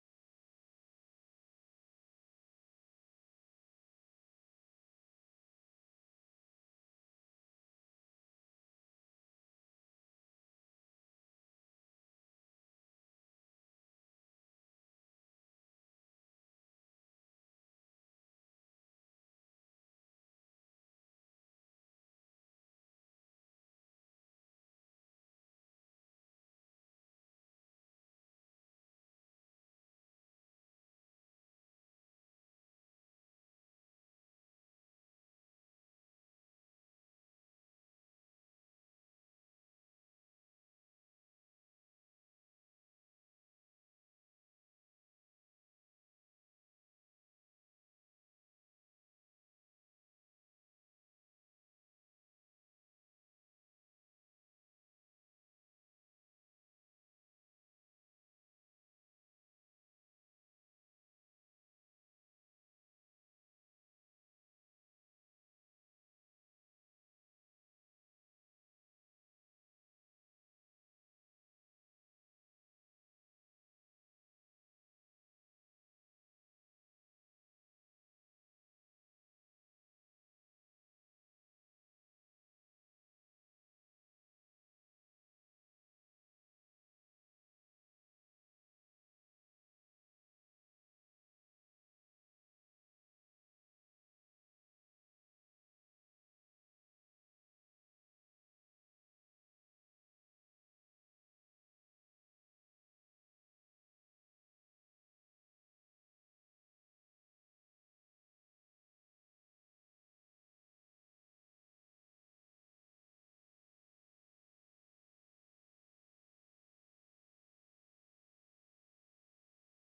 De vergadering wordt digitaal gehouden gezien de aangescherpte maatregelen.